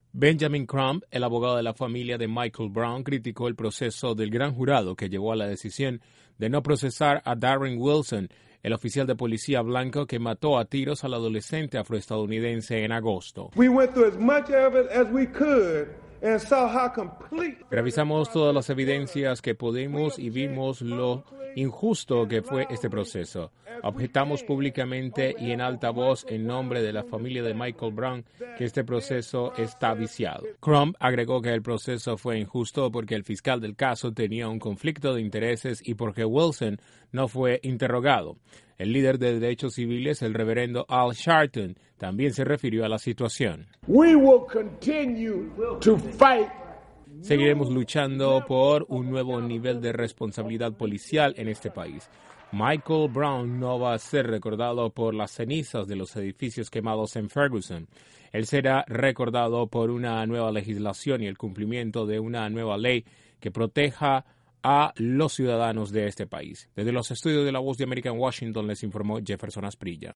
Los abogados de la familia de Michael Brown dijeron que el proceso no fue justo, porque el fiscal del caso tenía un conflicto de interés y Wilson no fue examinado de forma apropiada. Desde la Voz de América en Washington